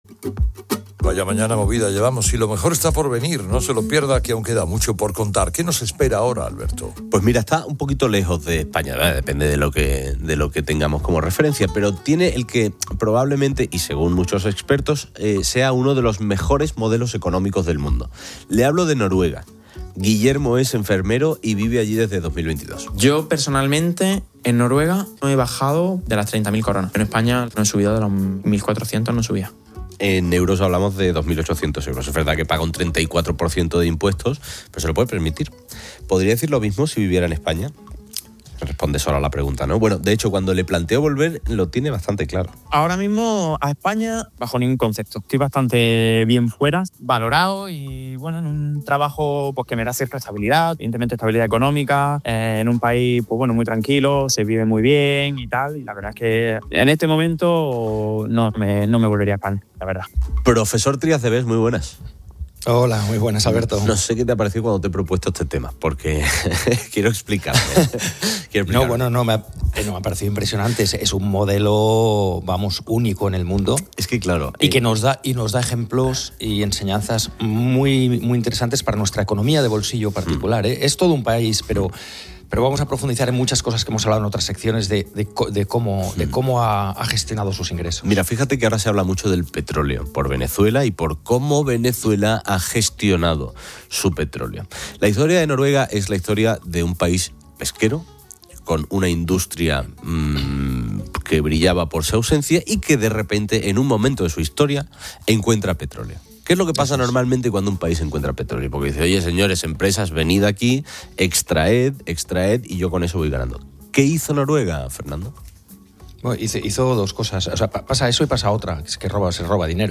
Fernando Trías de Bes, analista económico